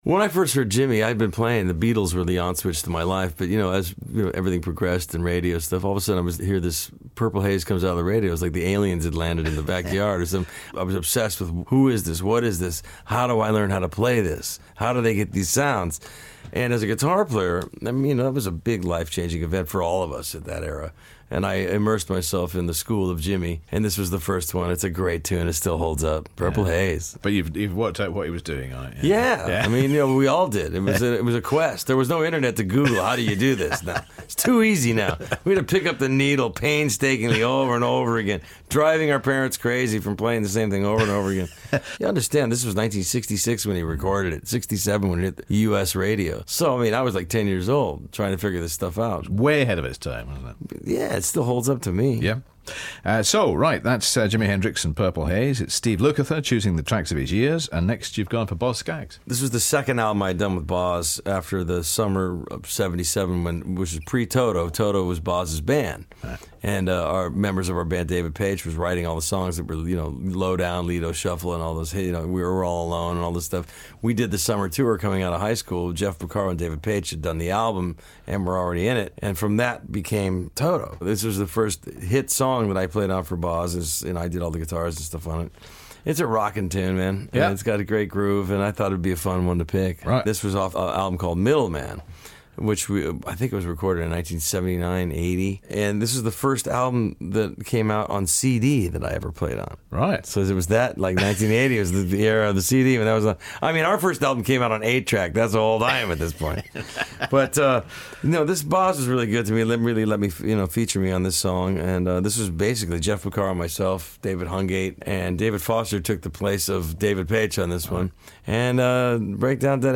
American guitarist and member of Toto - Steve Lukather talks about how his musical career was heavily influenced by Jimi Hendrix.